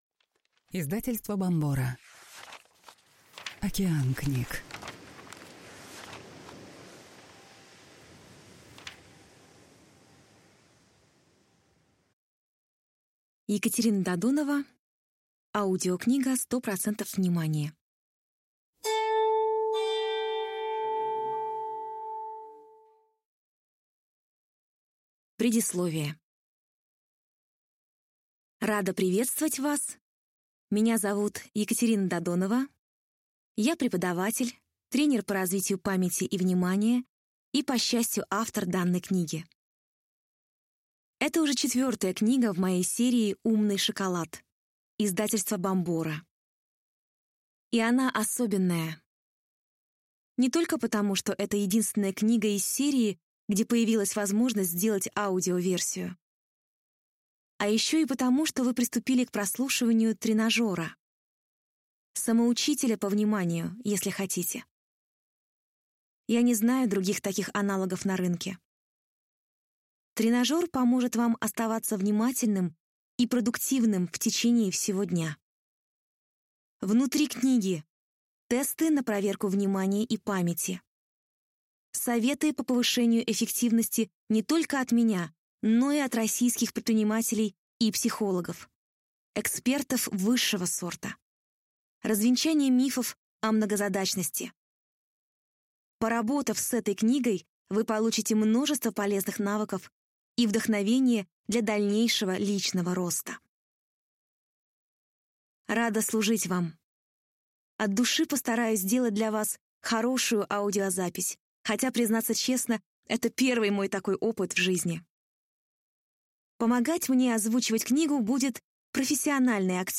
Аудиокнига 100% внимание. 50 лайфхаков, которые повысят концентрацию внимания | Библиотека аудиокниг